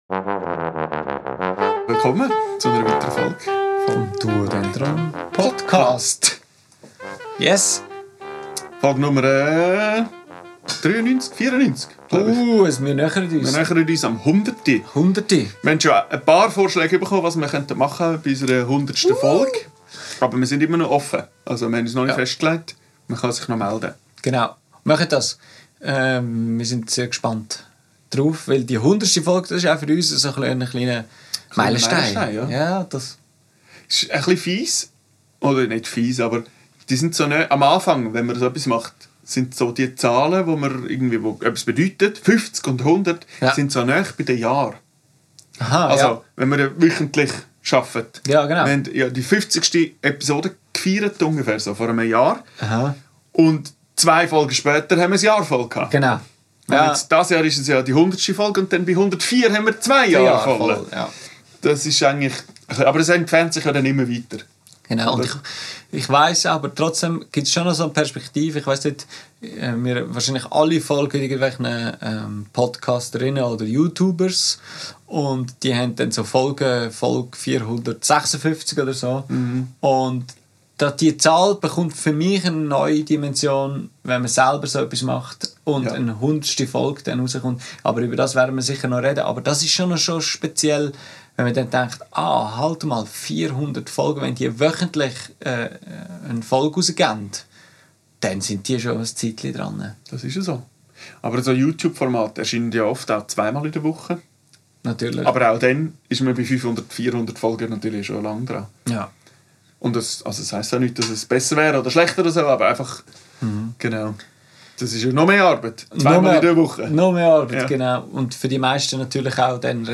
Aufgenommen am 30.12.2025 im Atelier